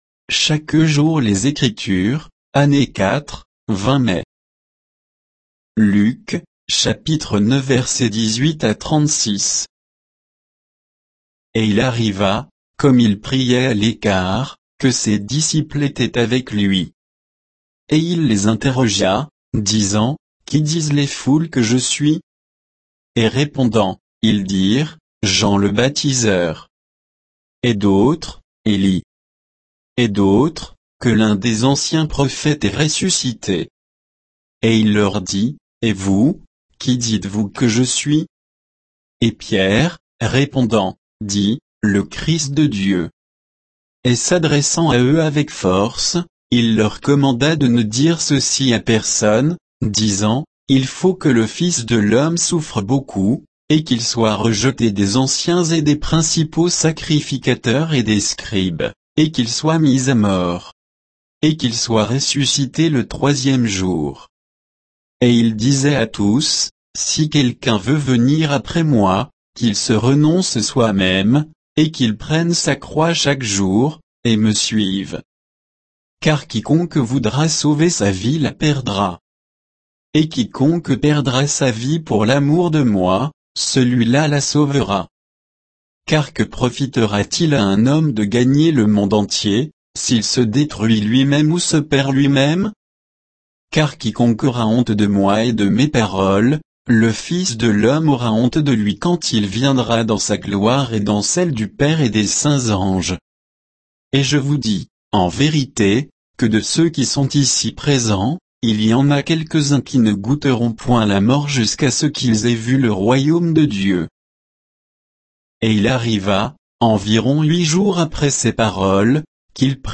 Méditation quoditienne de Chaque jour les Écritures sur Luc 9